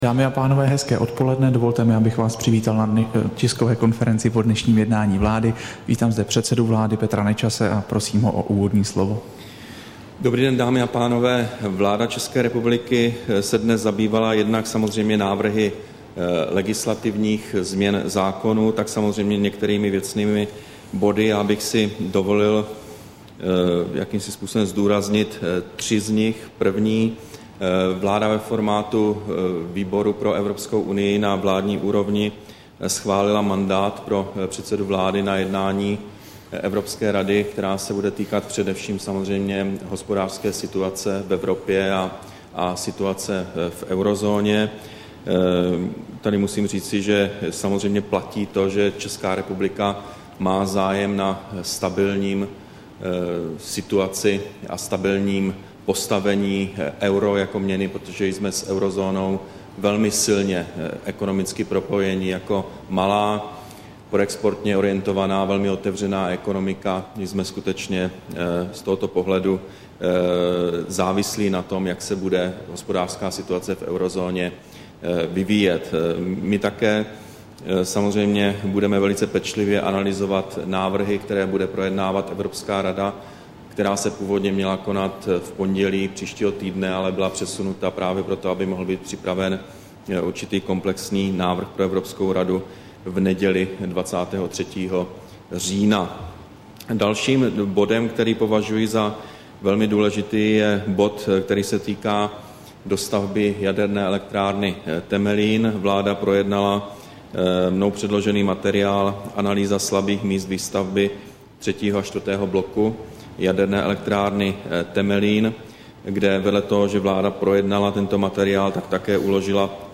Tisková konference po jednání vlády, 12. října 2011